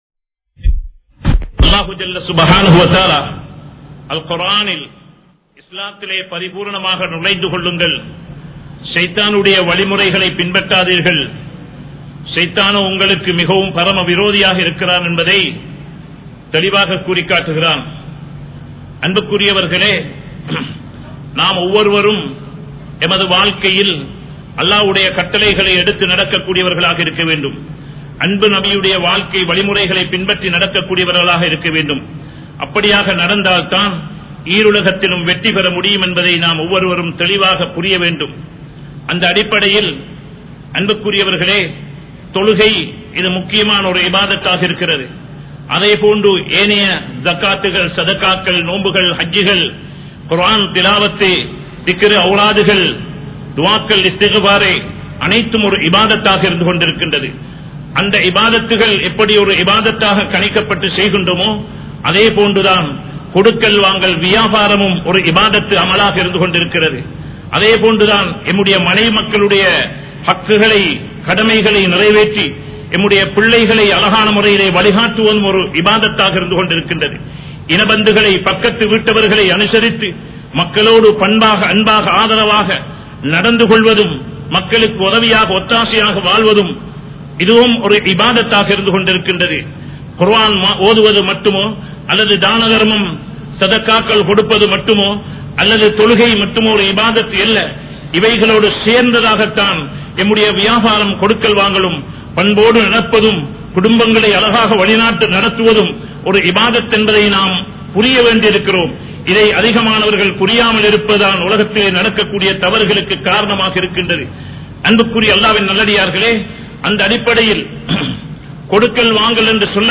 Viyafaaramum Indraya Muslimkalum (வியாபாரமும் இன்றைய முஸ்லிம்களும்) | Audio Bayans | All Ceylon Muslim Youth Community | Addalaichenai
Kollupitty Jumua Masjith